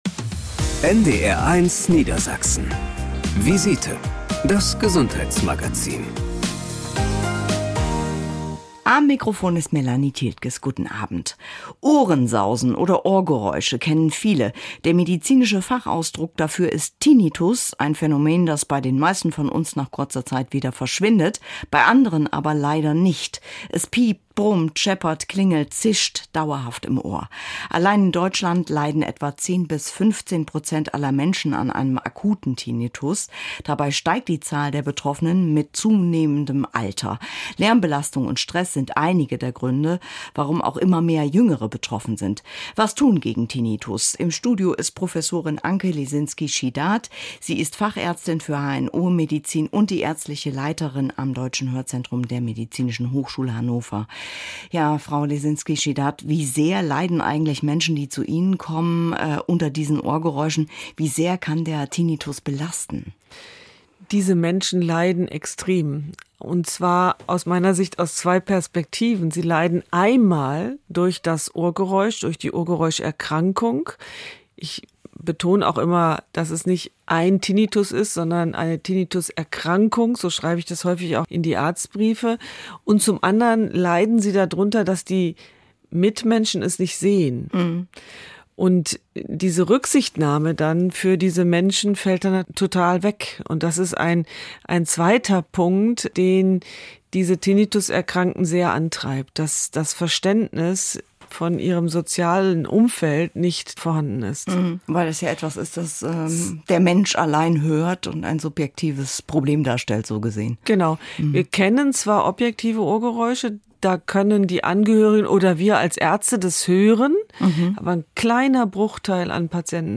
Eine Fachärztin erläutert in der Sendung, wodurch ein Tinnitus ausgelöst werden kann und wie die Therapie aussieht.